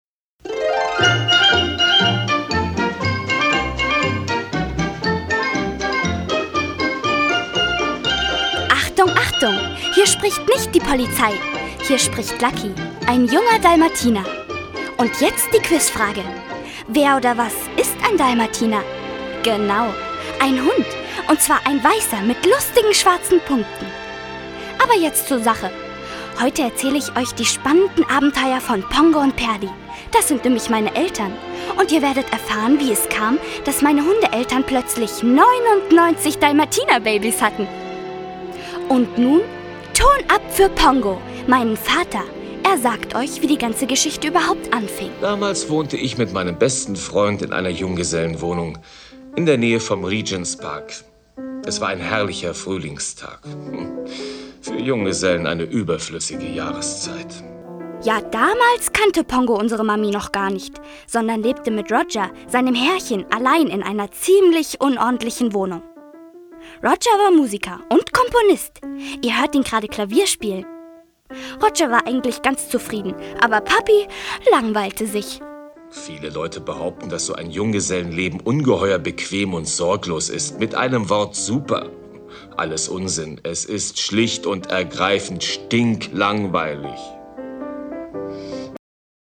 Doch als sie entführt werden, beginnt eine spannende Rettungsaktion, um die Kleinen schnell wieder mit ihren Eltern zu vereinen. Ein Disney-Klassiker mit den Original-Stimmen aus dem Kinofilm.
Hörspiel